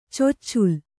♪ coccul